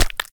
Minecraft Version Minecraft Version snapshot Latest Release | Latest Snapshot snapshot / assets / minecraft / sounds / block / frogspawn / break2.ogg Compare With Compare With Latest Release | Latest Snapshot